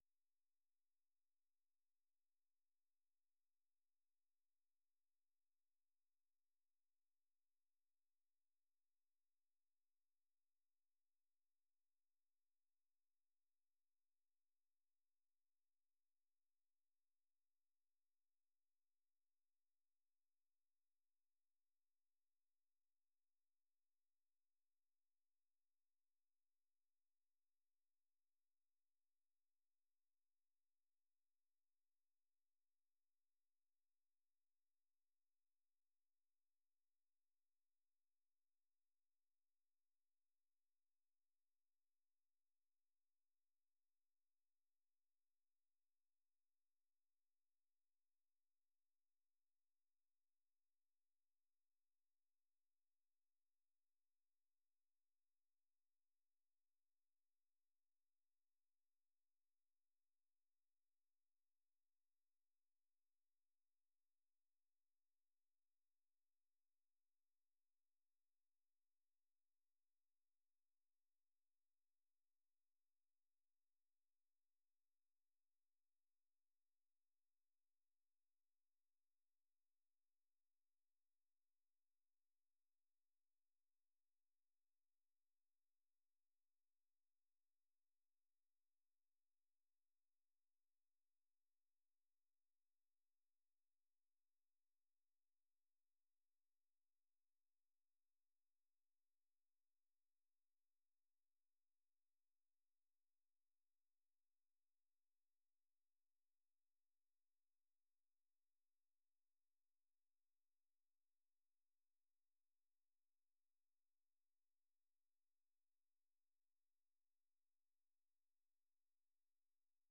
အပြည်ပြည်ဆိုင်ရာ စံတော်ချိန် ၂၃၃၀ ၊ မြန်မာစံတော်ချိန် နံနက် ၆ နာရီကနေ ၇ နာရီထိ (၁) နာရီကြာ ထုတ်လွှင့်နေတဲ့ ဒီ ရေဒီယိုအစီအစဉ်မှာ မြန်မာ၊ ဒေသတွင်းနဲ့ နိုင်ငံတကာ သတင်းနဲ့ သတင်းဆောင်းပါးတွေ သီတင်းပတ်စဉ်ကဏ္ဍတွေကို နားဆင်နိုင်ပါတယ်။